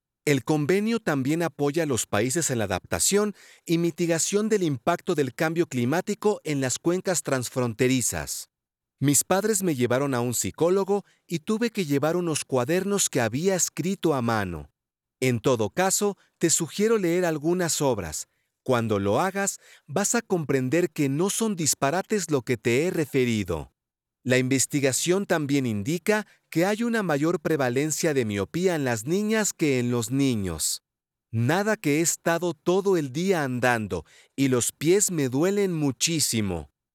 数据堂TTS录音棚通过清华大学建筑环境检测中心检测，达到专业级NR15声学标准，混响时间小于0.1秒，背景噪音小于20dB(A)。
墨西哥西班牙语，浑厚男声